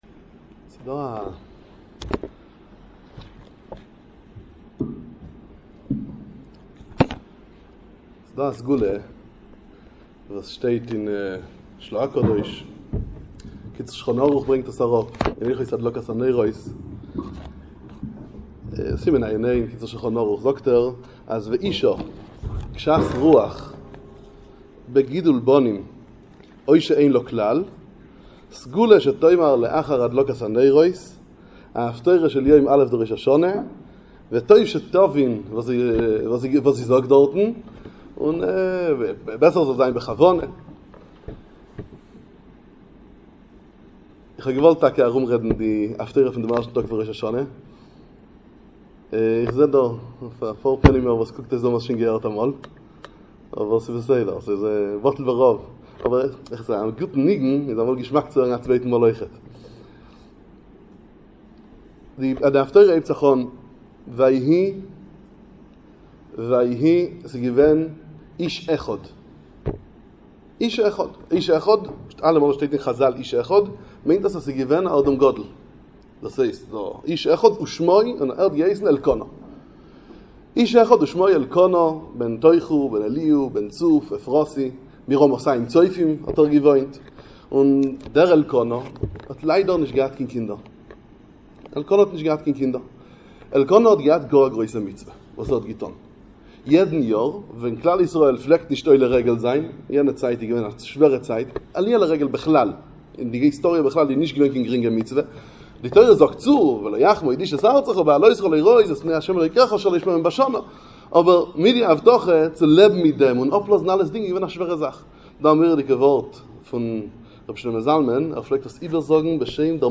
כינוס הורים בחיידר אור התורה בית שמש